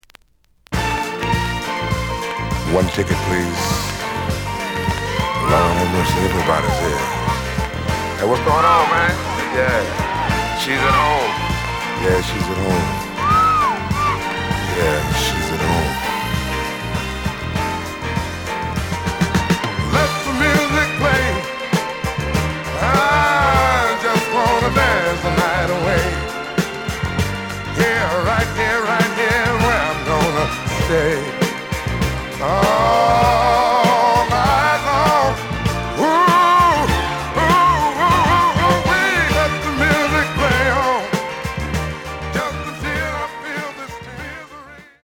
The audio sample is recorded from the actual item.
●Genre: Disco
Some click noise on both sides due to scratches.)